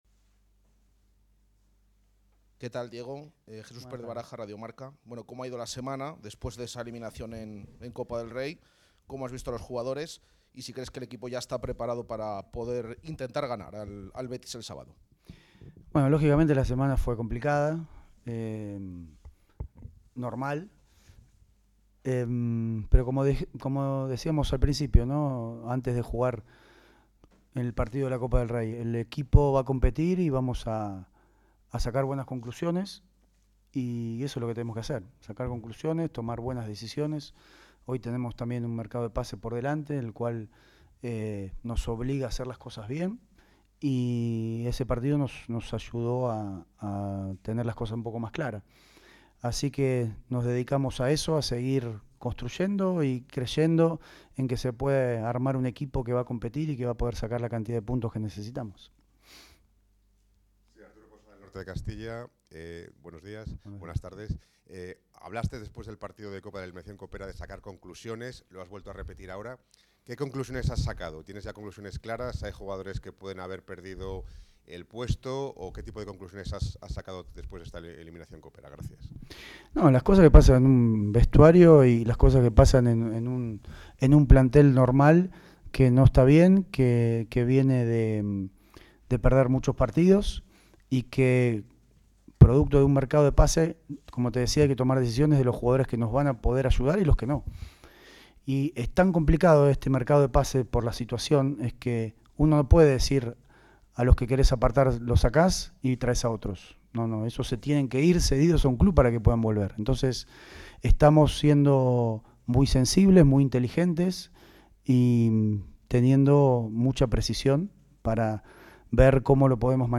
El entrenador blanquivioleta respondió a las preguntas de los periodistas a dos días del duelo ante los béticos, después de caer eliminados en Copa del Rey, y en pleno mercado invernal de fichajes.